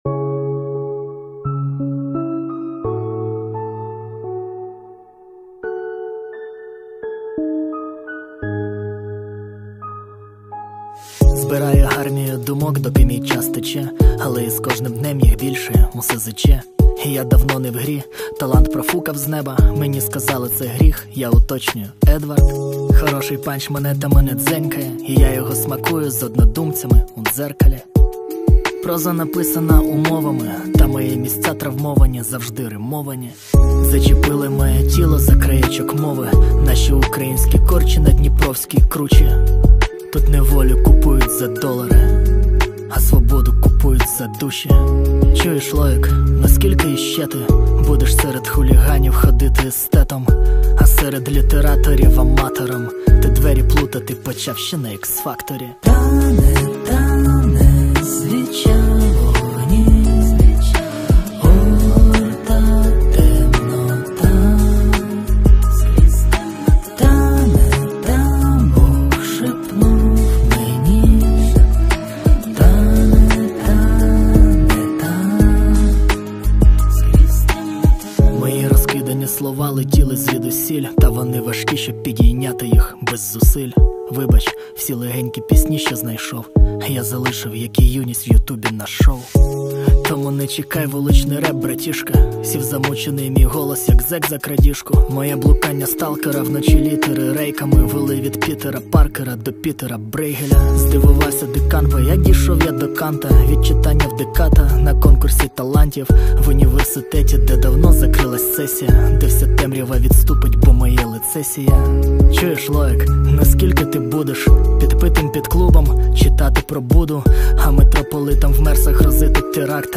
Жанр: Реп / хіп-хоп